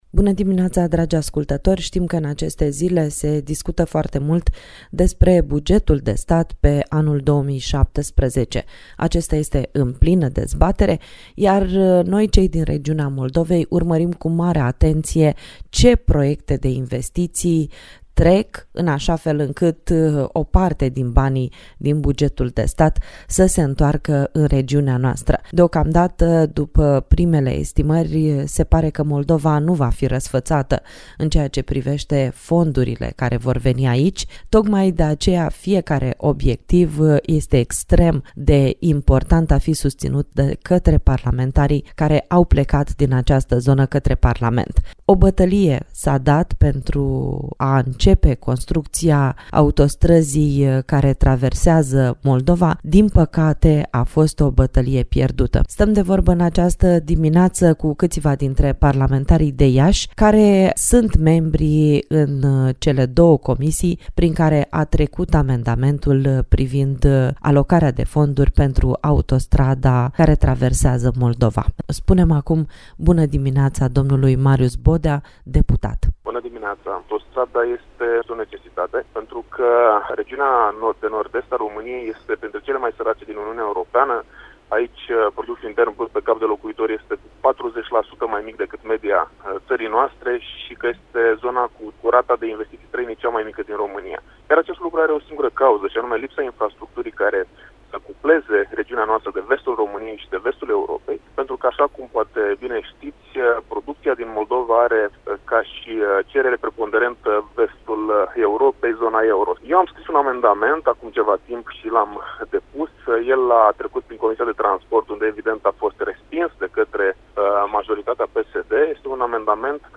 Cum s-a votat în Comisia de buget, care au fost surprizele la vot și cum ar fi schimbat fața Moldovei realizarea autostrăzii, ne-au spus la Tema Zilei, în emisiunea Buna Dimineața, Marius Bodea, deputat, Iulia Scântei și Victorel Lupu- senatori.